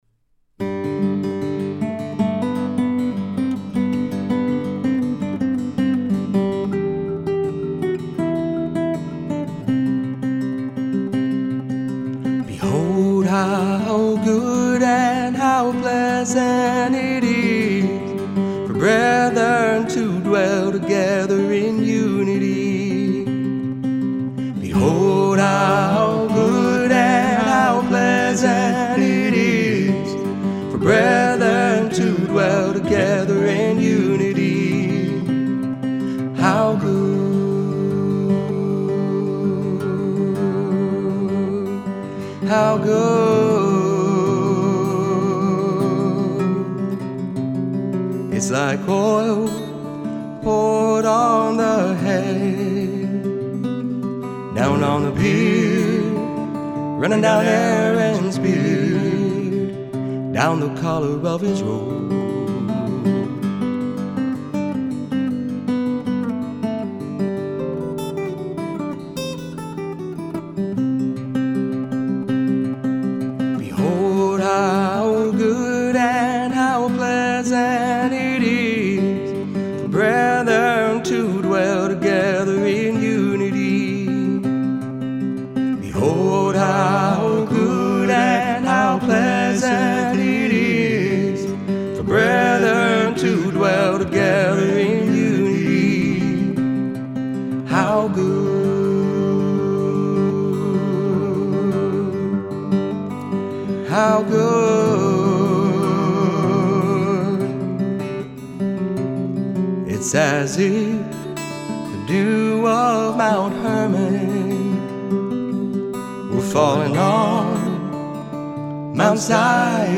Vocals and Guitar